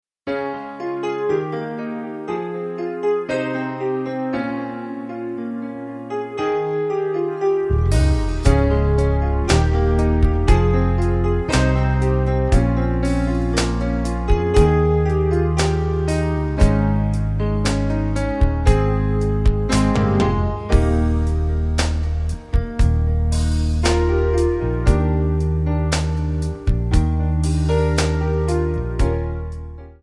Eb
MPEG 1 Layer 3 (Stereo)
Backing track Karaoke
Pop, 1990s